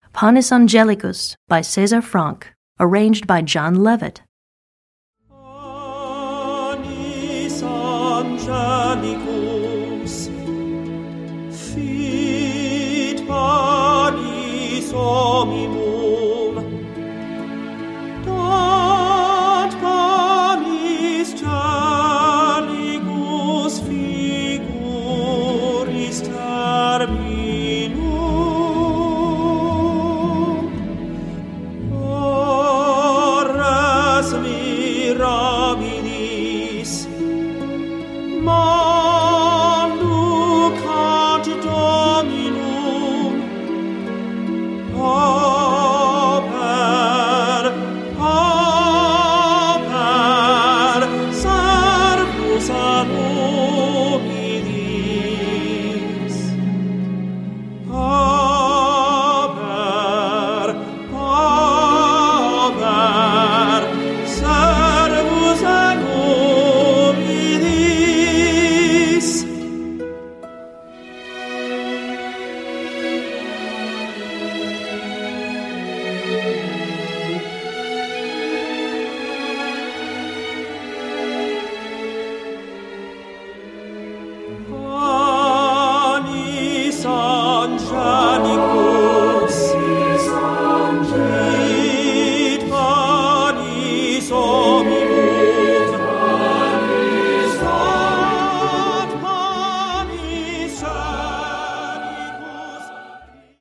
Voicing: Chamber Orchestra